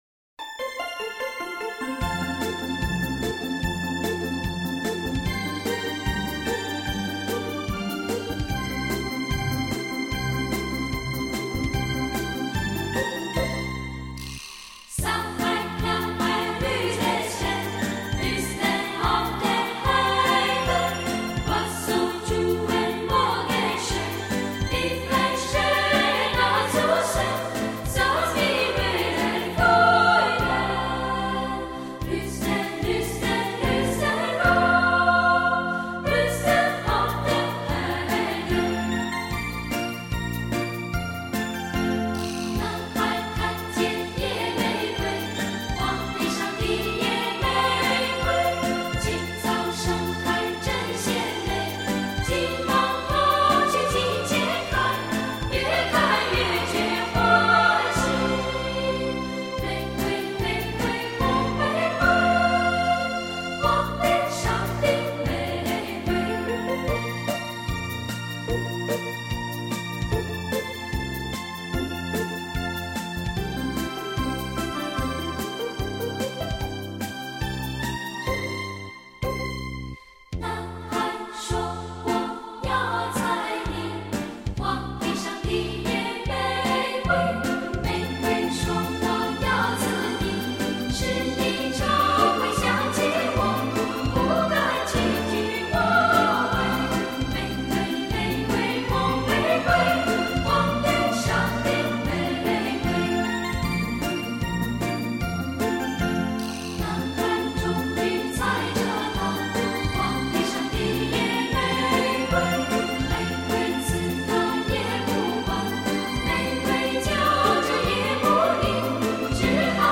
优质的录音技术更让专辑细致动听。
令人迷醉的西洋民谣情歌，在清新的童音中，呈现了另一番风格独具的浪漫，收服每一颗多情的心。